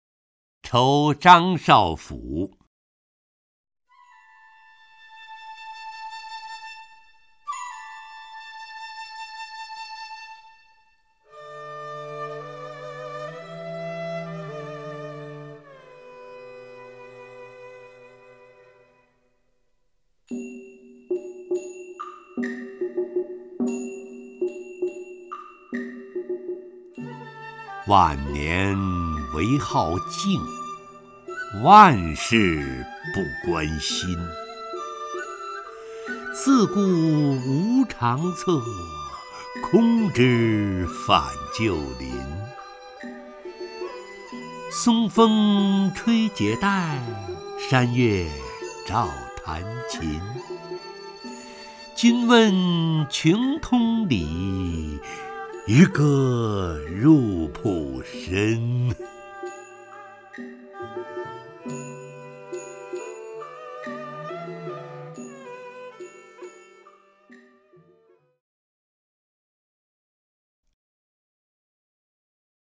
陈醇朗诵：《酬张少府》(（唐）王维) （唐）王维 名家朗诵欣赏陈醇 语文PLUS